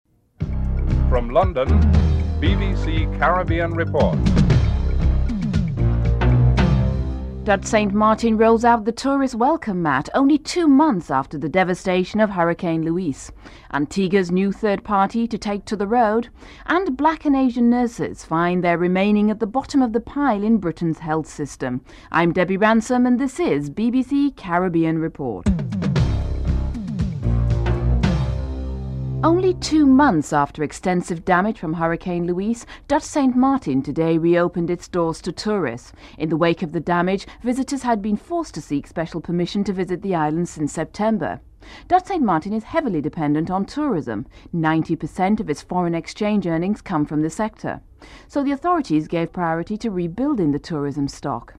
In this report Dutch St. Martin rolls out the tourist welcome mat only two months after the devastation of hurricane Luis. The Lt. Governor of St. Martin, Dennis Richardson comments on the emphasis of getting the tourism stock back on track and how this affected the rebuilding of locals' homes. In Guyana, the hearing into the cyanide wastes spill of the Omai Gold Mines focused on the absence of environmental protection legislation in the country.